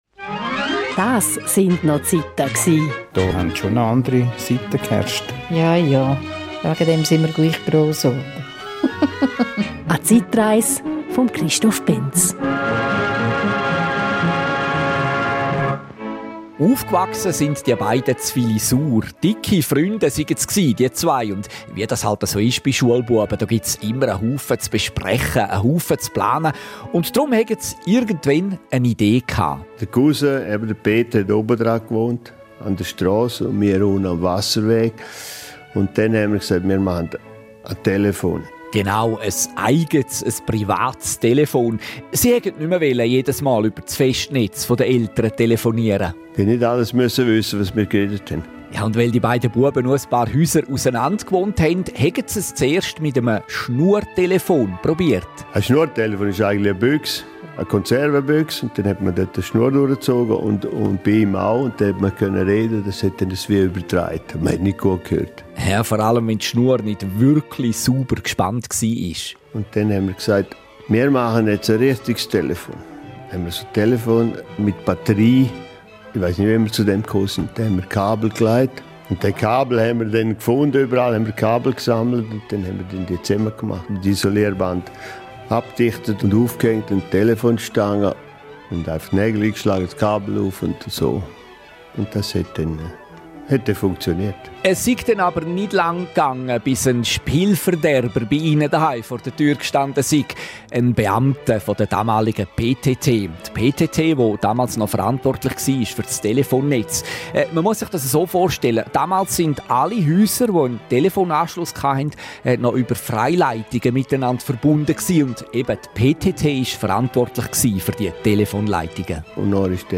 In der RSO-Serie «Das sind no Ziita gsi» erzählen Frauen und Männer aus der Region von früher.